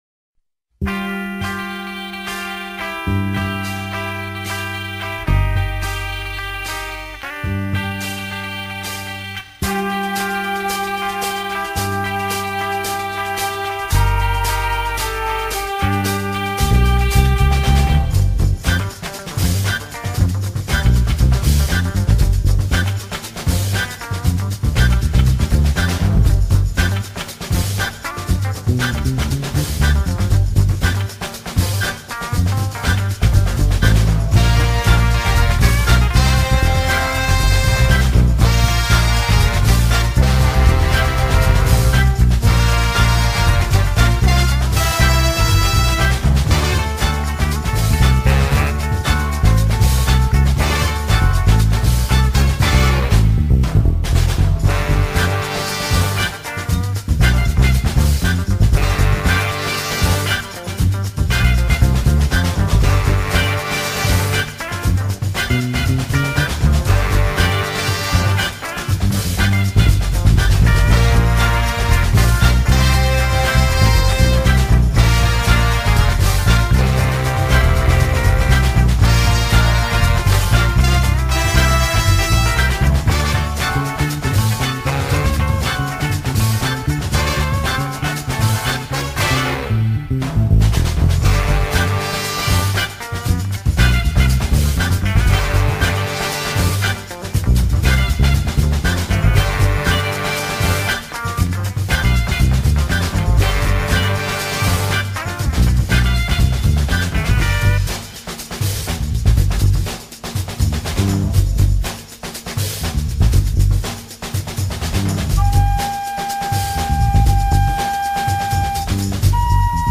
Le Jazz-Funk